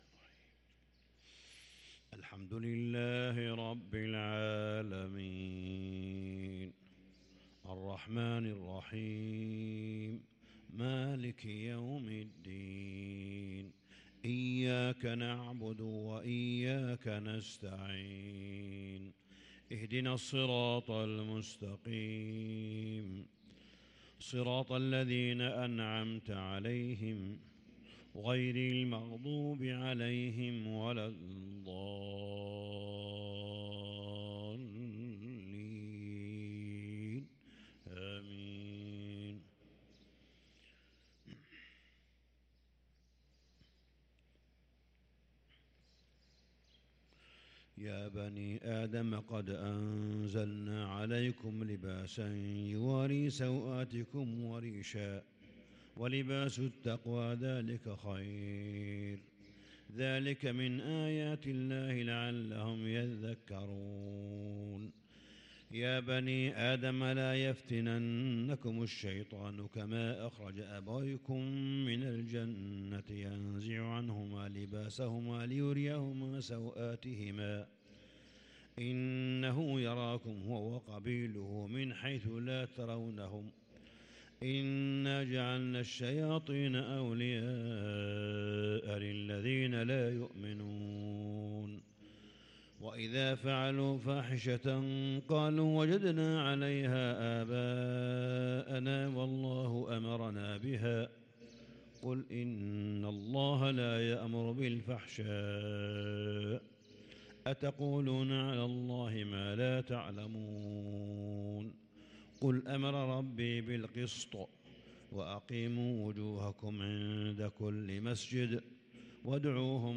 صلاة الفجر للقارئ صالح بن حميد 15 رمضان 1443 هـ
تِلَاوَات الْحَرَمَيْن .